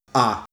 Vowels
ALike the a in far